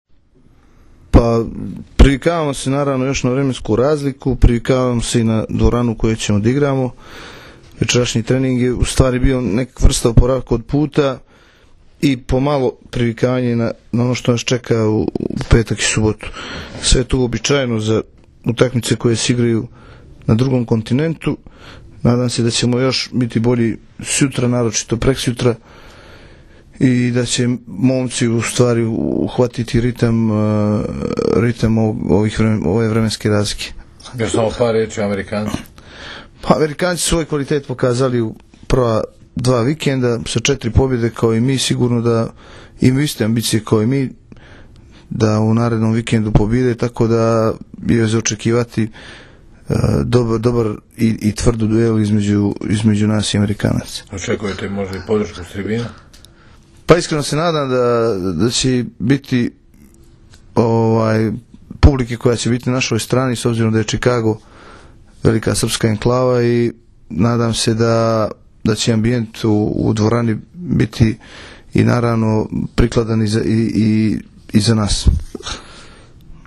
IZJAVA IGORA KOLAKOVIĆA